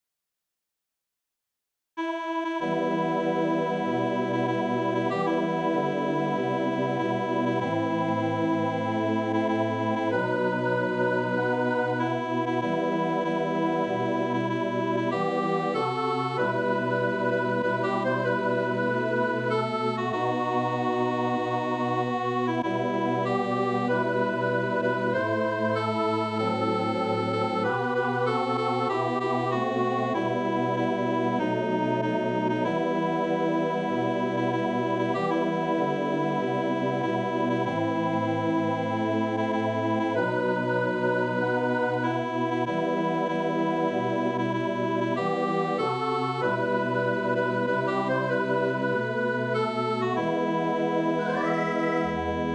This is a Halloween song for children.
Vocal Solo